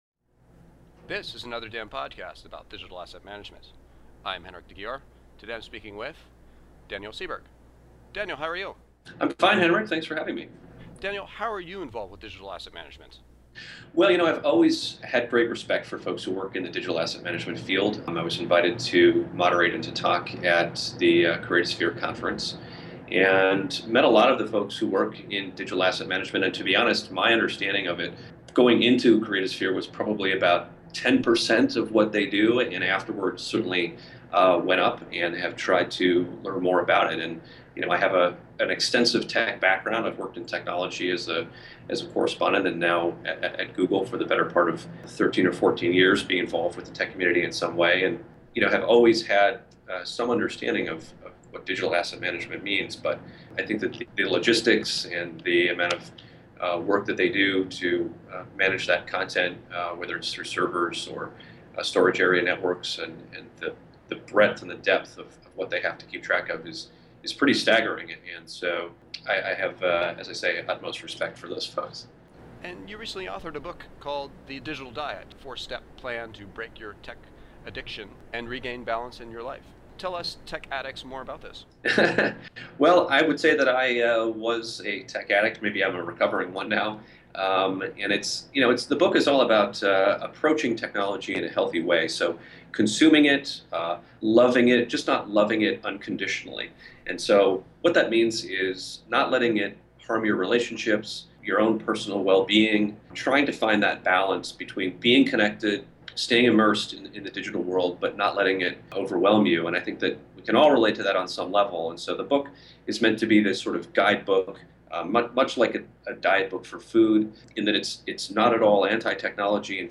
Another DAM Podcast interview